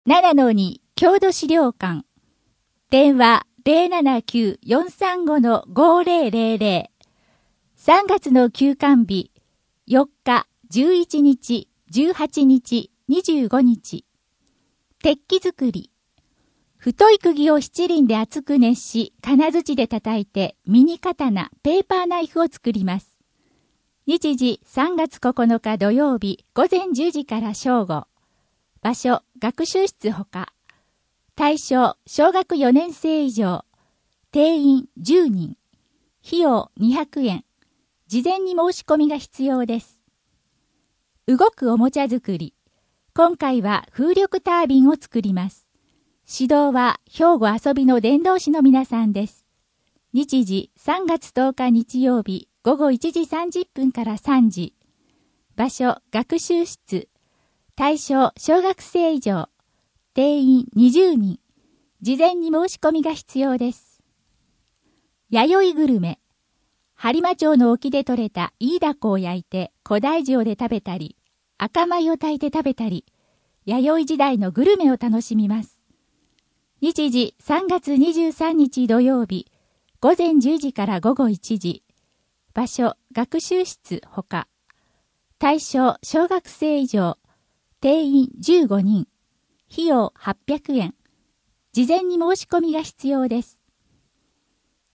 声の「広報はりま」3月号
声の「広報はりま」はボランティアグループ「のぎく」のご協力により作成されています。